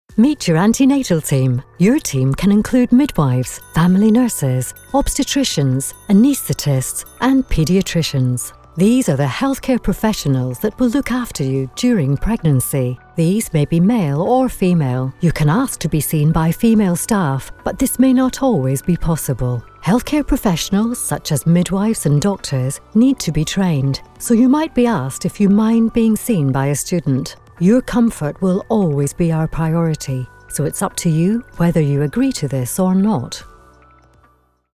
Apprentissage en ligne
J'ai un léger accent écossais et ma voix a été décrite comme sophistiquée, douce, chaleureuse et autoritaire.
Microphone Sennheiser
Anglais (écossais)
Âge moyen